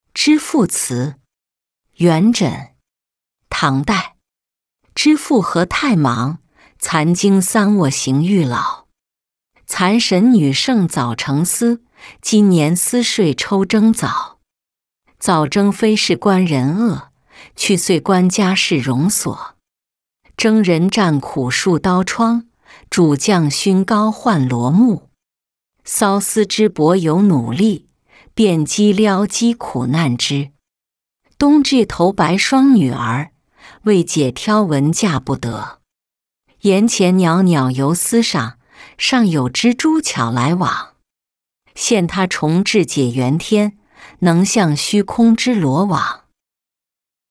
织妇词-音频朗读